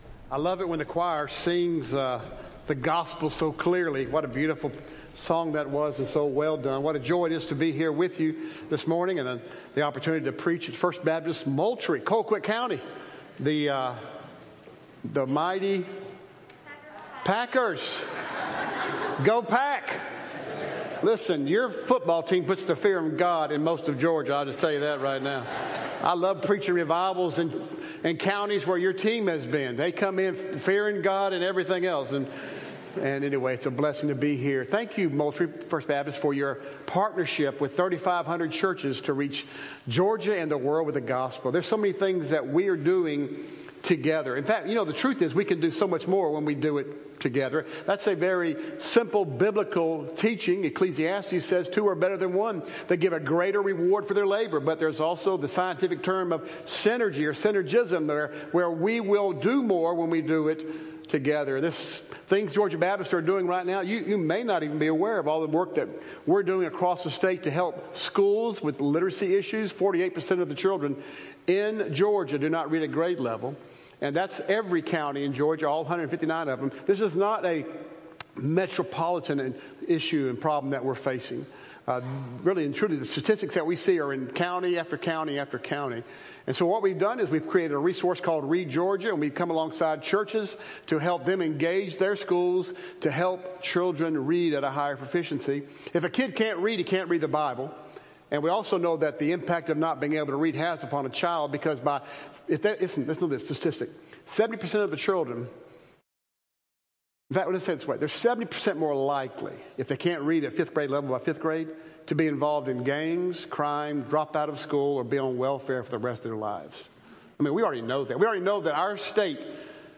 A message from the series "First Things First."